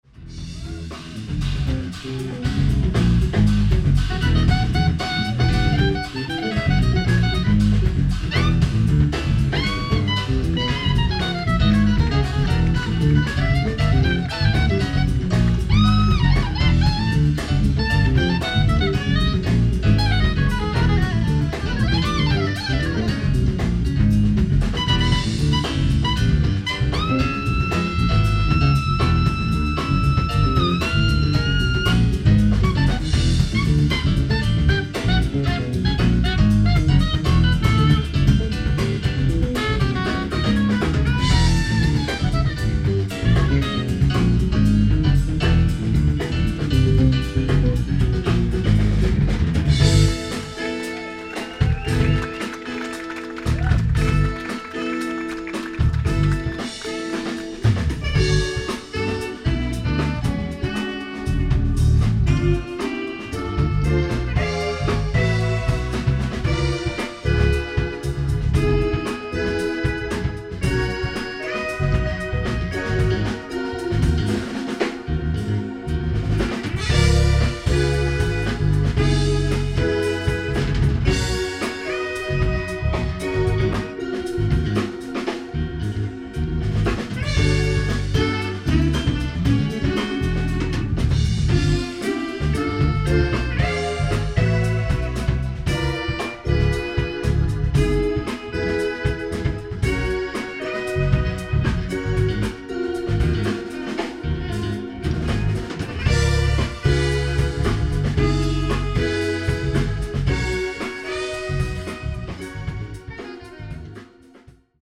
ライブ・アット・ブルーノート、ニューヨーク 11/12/1996
演奏、音質と最高のパフォーマンス！！
※試聴用に実際より音質を落としています。